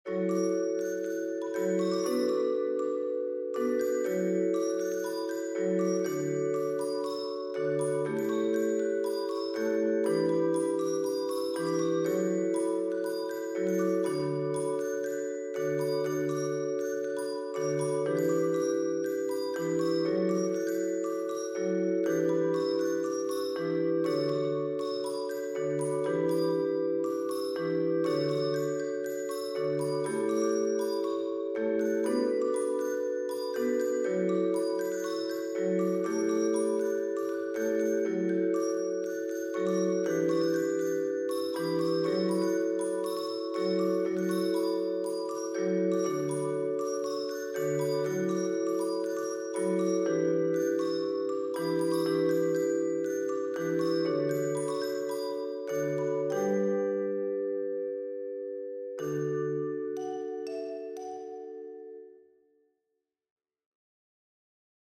We inputted 3 different images into the code, and produced music xml files which we then inputted into MuseScore to give us sheet music. For two of these we have recorded videos of us playing the music created, using different instruments we play to try to match our interpretation of the artwork and the image.